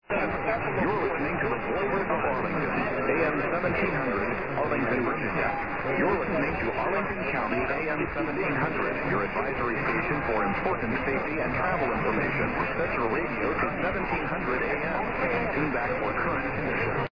relatively poor conditions.